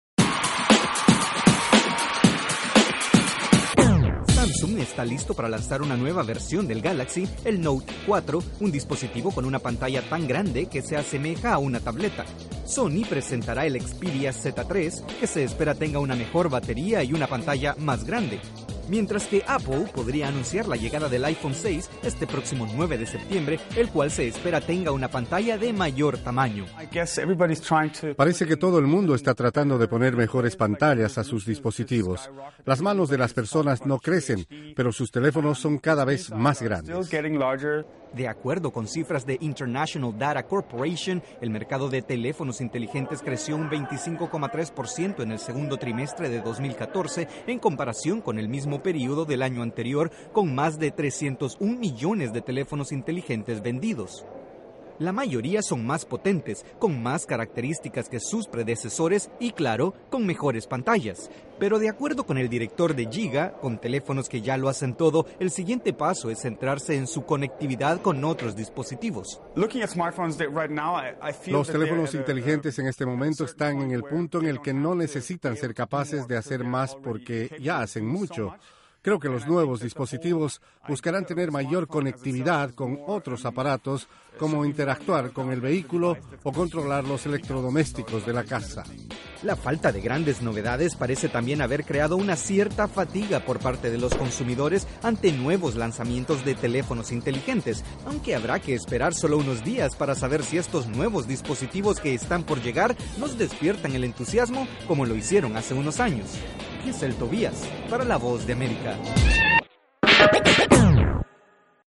Samsung, Sony, Microsoft y, próximamente, Apple, están por mostrar sus nuevos teléfonos inteligentes. ¿Pero qué podemos esperar de estos dispositivos que ya son capaces de hacerlo casi todo? Desde los estudios de la Voz de América en Washington informa